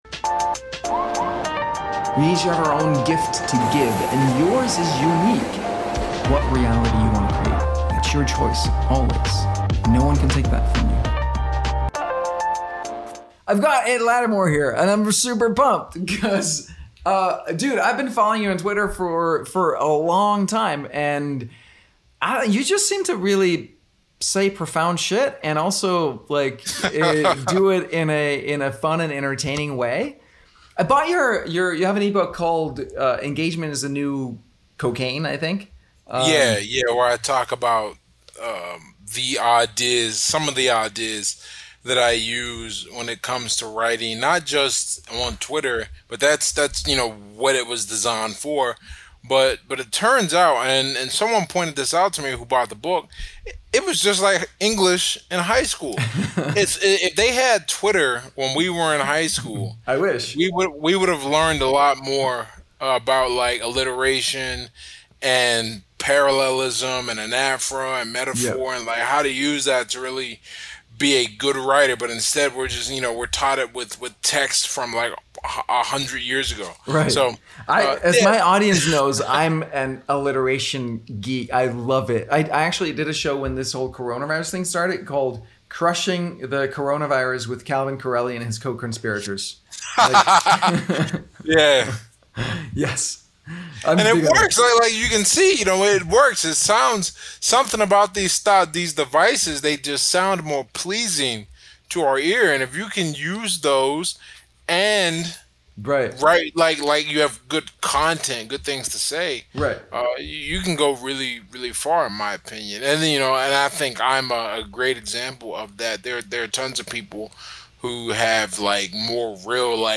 Making Money Isn't An Indication Of Anything Interview